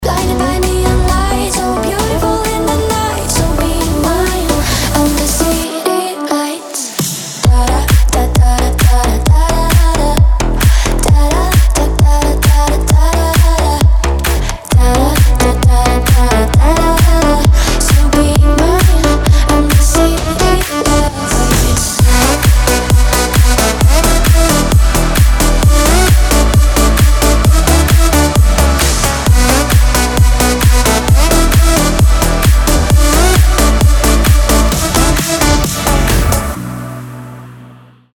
• Качество: 320, Stereo
shuffle dance
electro-funk